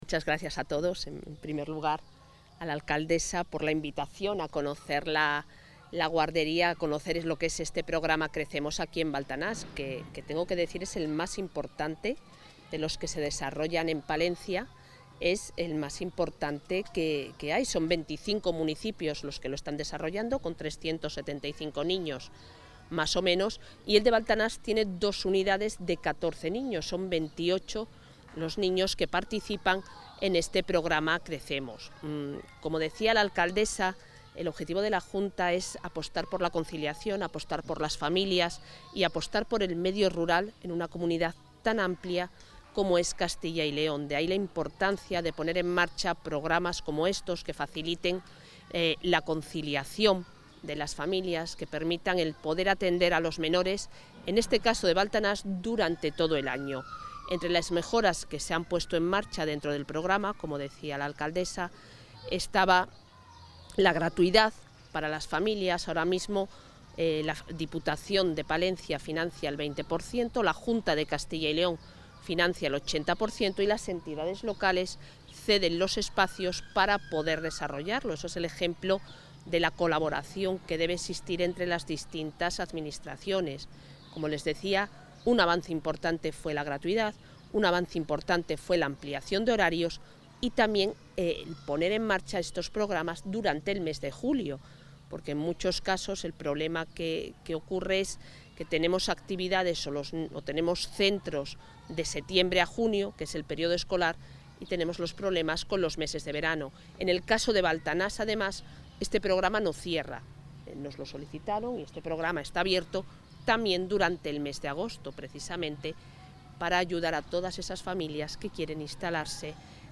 Declaraciones de la consejera.